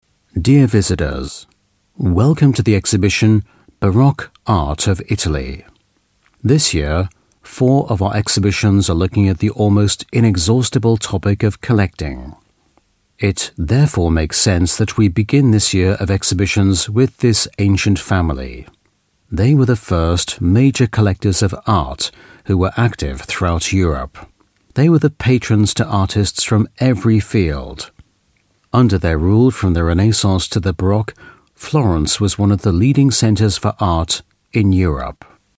Native Speaker
Audioguides